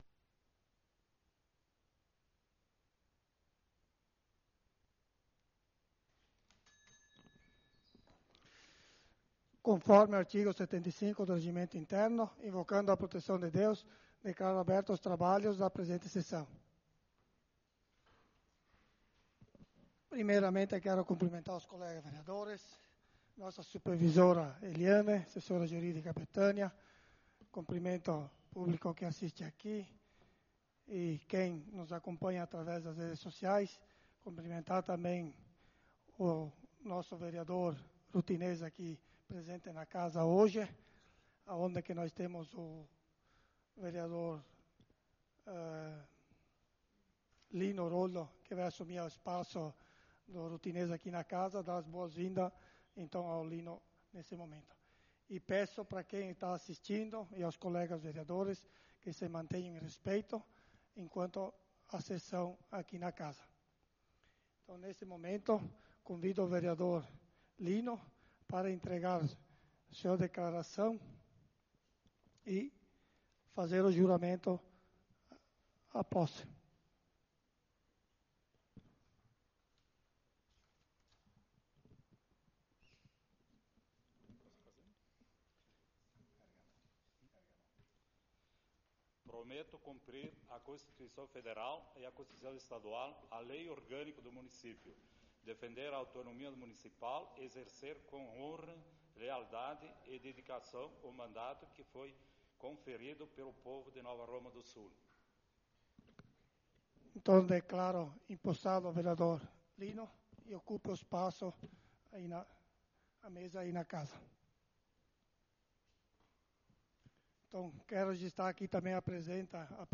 Sessão Ordinária do dia 07/05/2025
Câmara de Vereadores de Nova Roma do Sul